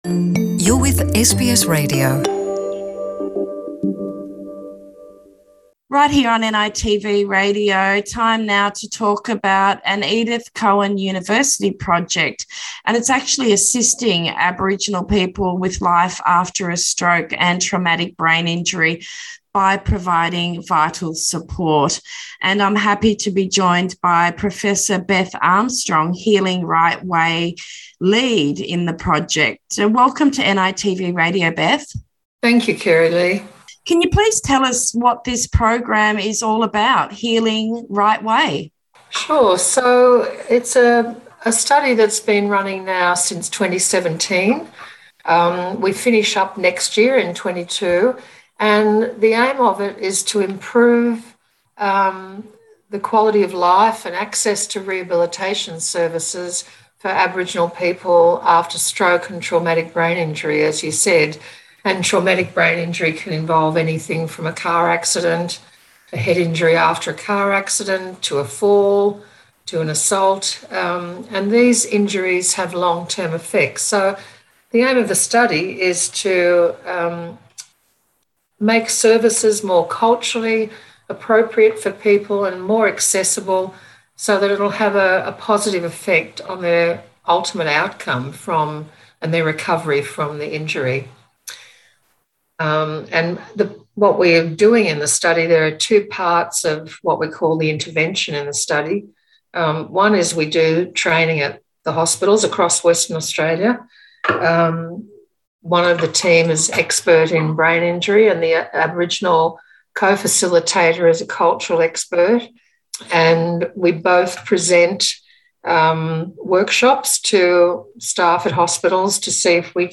Interviews with Healing Right Way